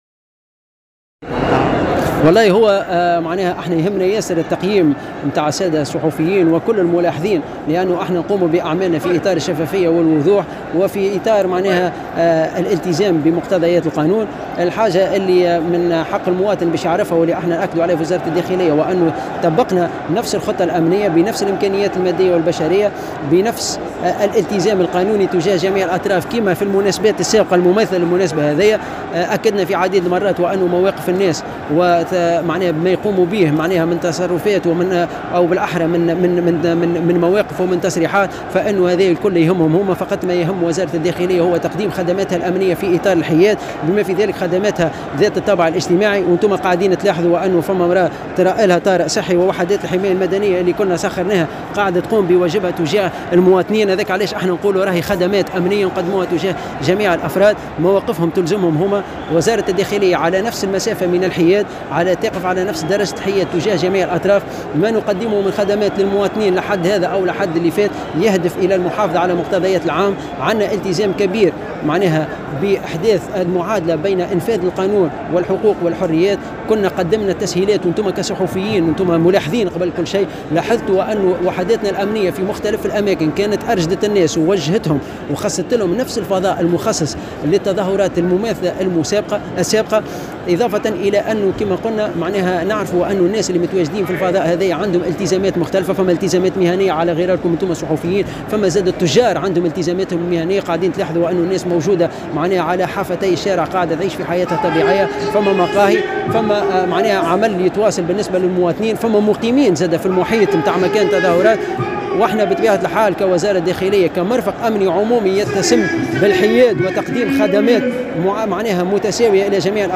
في تصريح لمراسل الجوهرة أف أم